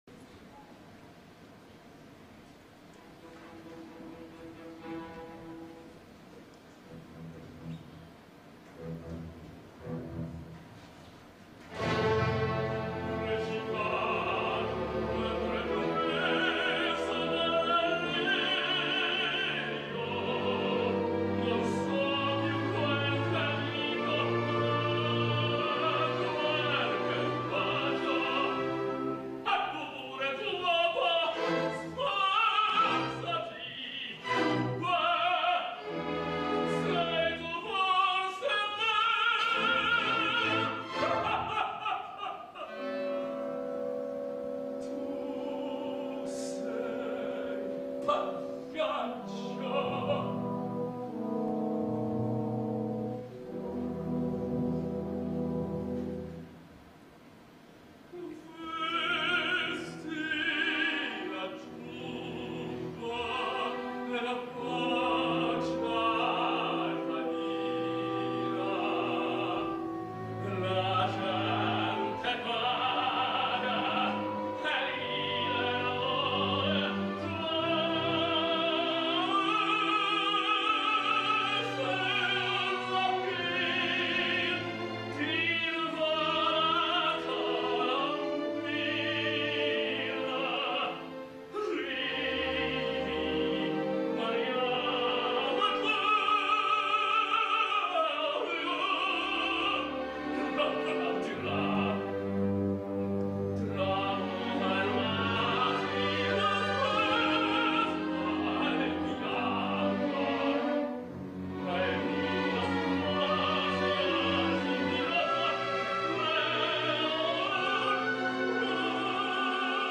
Opernsänger, Tenor
Arioso Canio